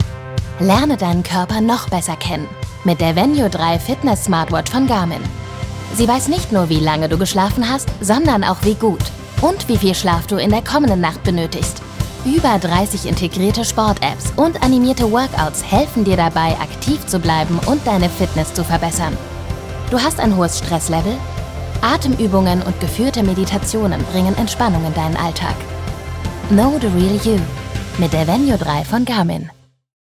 sehr variabel
Jung (18-30)
Schwäbisch
Commercial (Werbung)